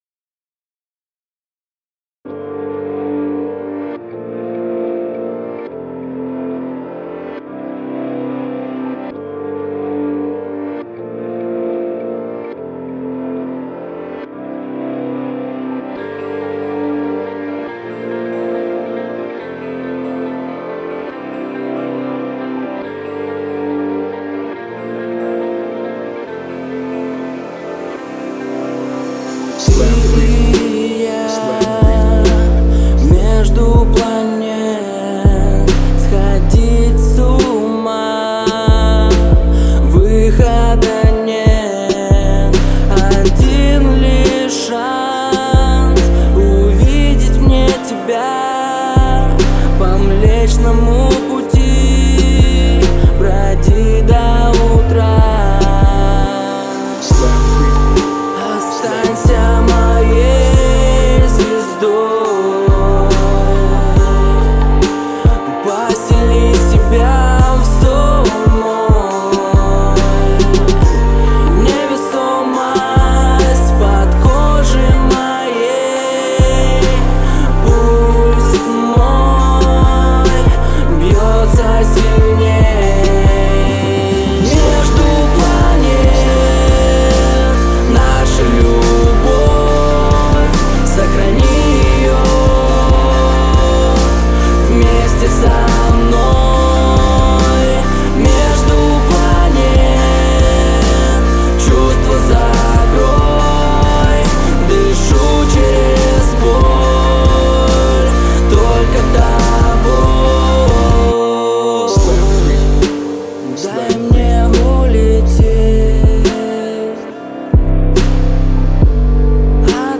Прошу оценить сведение Pop\Hip-Hop
С низом согласен как и комментарии выше - его недостаточно. На вокале можно ревера и меньше.